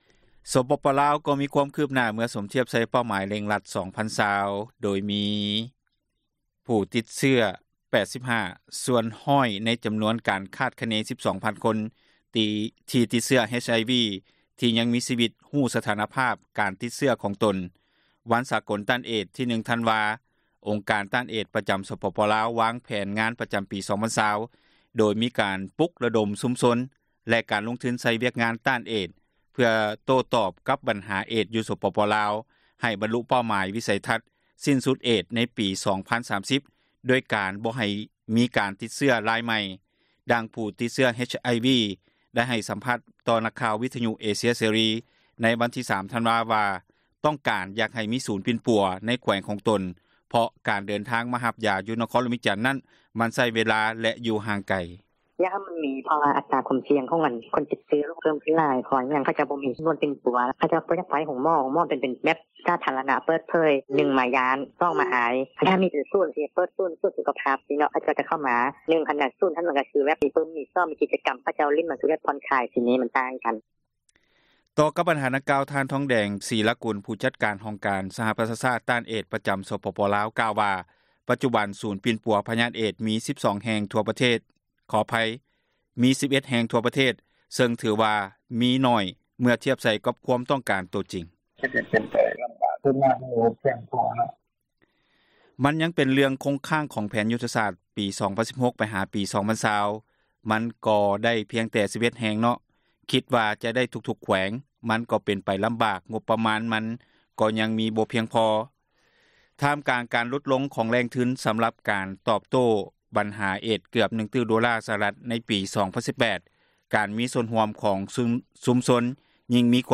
ດັ່ງຜູ້ຕິດເຊື້ອ HIV ໄດ້ໃຫ້ສຳພາດ ຕໍ່ນັກຂ່າວ RFA ໃນວັນທີ 03 ທັນວາ ວ່າຕ້ອງການຢາກໃຫ້ມີສູນປີ່ນປົວ ໃນແຂວງຂອງຕົນ ເພາະການເດີນທາງມາຮັບ ຢາຢູ່ ນະຄອນຫຼວງວຽງຈັນ ນັ້ນມັນໃຊ້ເວລາ ແລະ ຢູ່ຫ່າງໄກ: